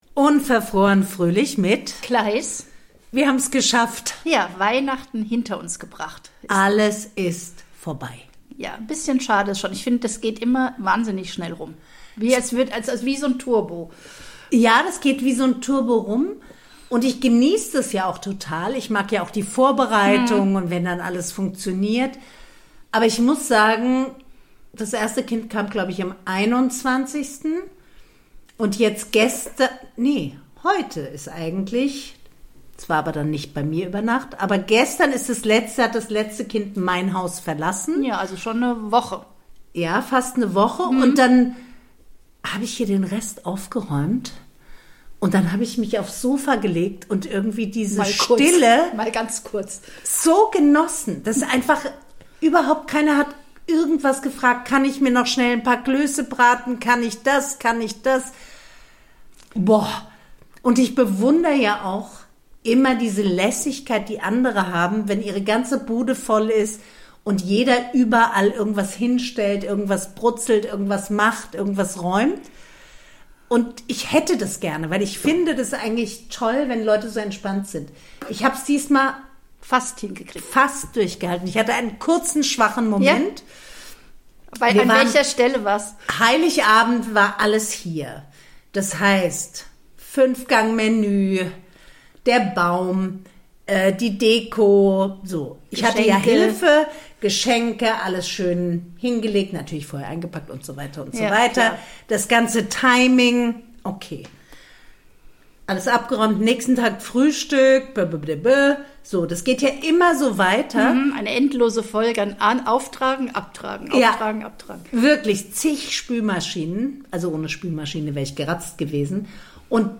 reden die beiden Podcasterinnen über die Weihnachtsfeiertage, das Stillegefühl zwischen den Jahren und die Völlerei rund um die Uhr.